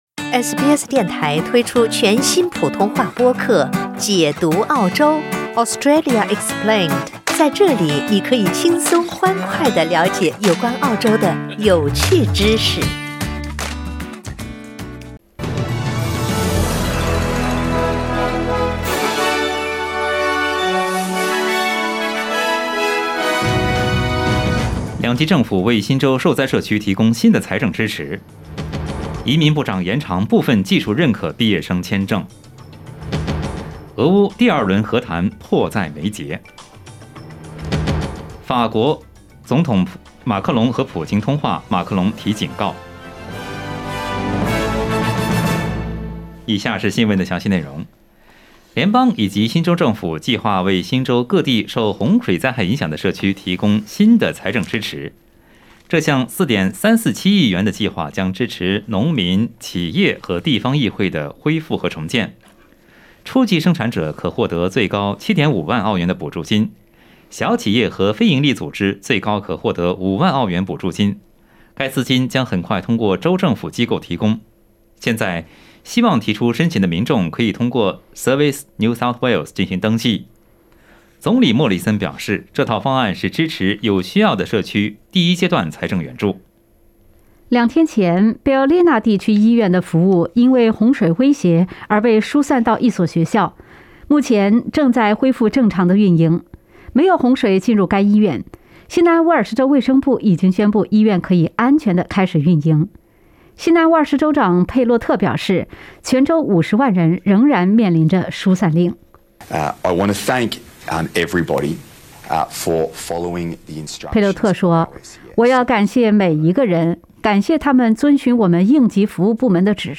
SBS早新闻（3月4日）
SBS Mandarin morning news Source: Getty Images